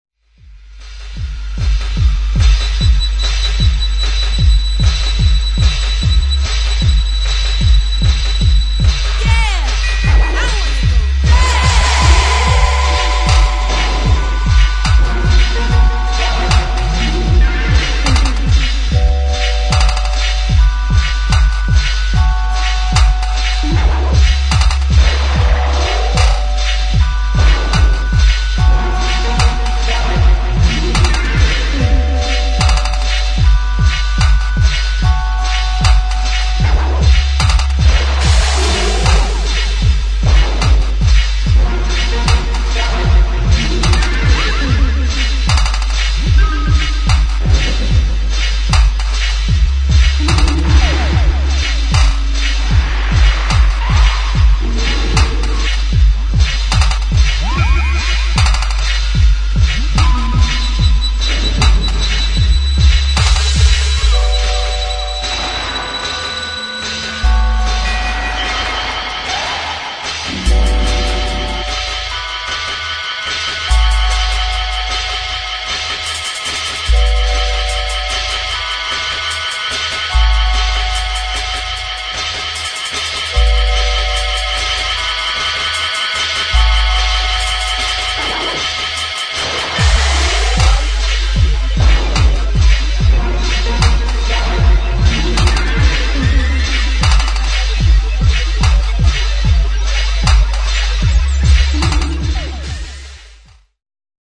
2. DUBSTEP | BASS
[ BASS / DUB / DRUM'N'BASS ]